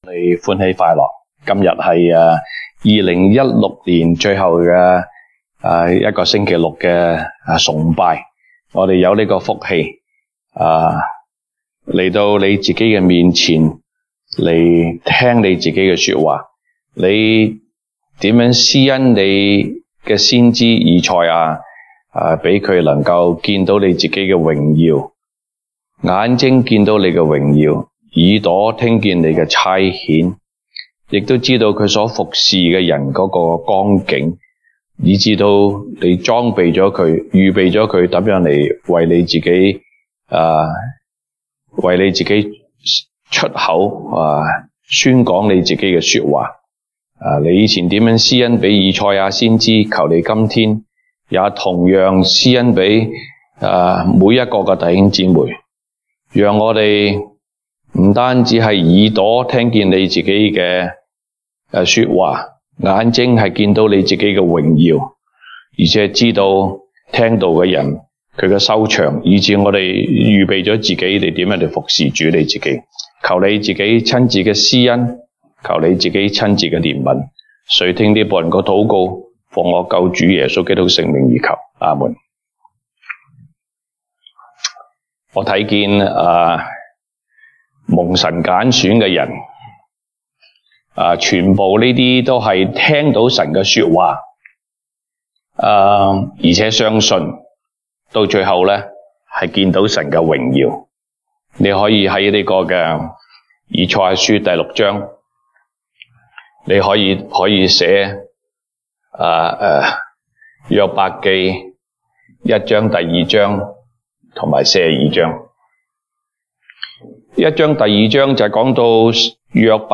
東北堂證道 (粵語) North Side: 先知蒙神差遣